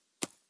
Dirt Foot Step 3.wav